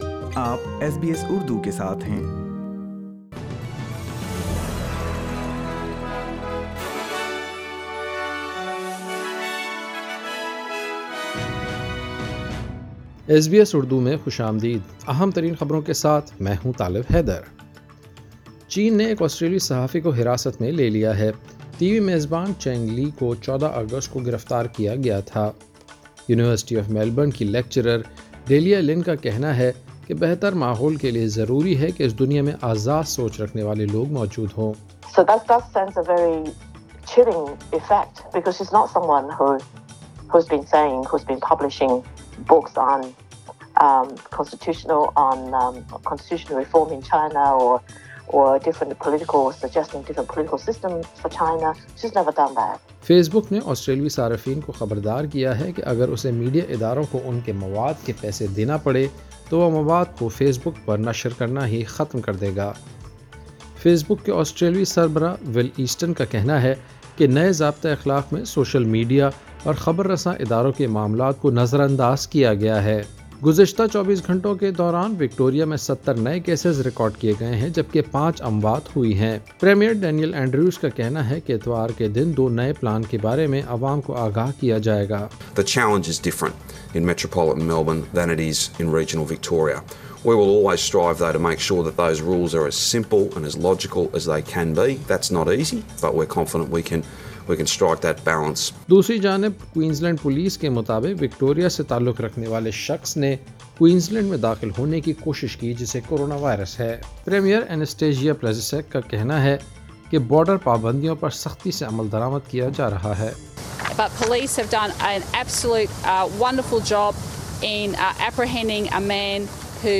سنیئے آسٹریلوی خبریں اردو میں۔